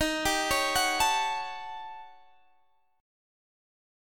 Listen to Eb7#9b5 strummed